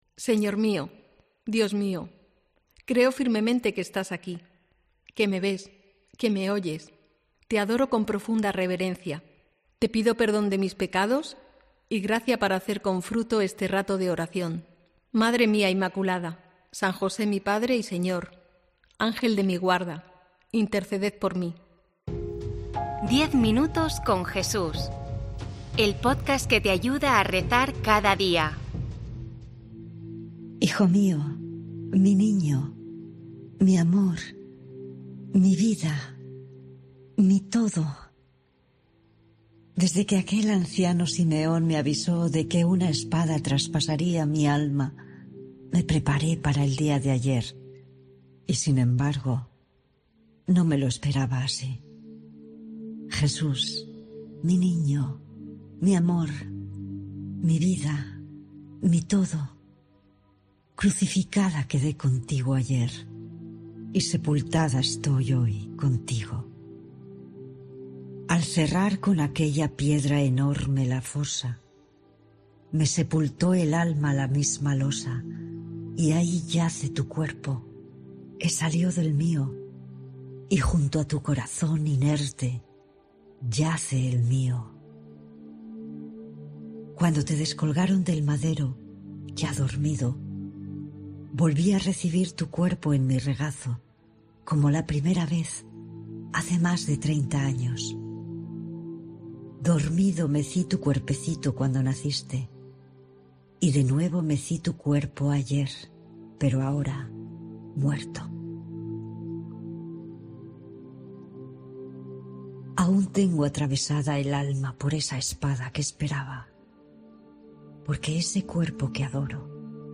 Redacción digital Madrid - Publicado el 27 mar 2024, 18:36 1 min lectura Descargar Facebook Twitter Whatsapp Telegram Enviar por email Copiar enlace COPE incorpora a su oferta de podcats '10 minutos con Jesús', una meditación diaria en formato podcast centrada en el Evangelio , en la que se proponen reflexiones y se ofrecen pinceladas sobre la vida de Jesucristo.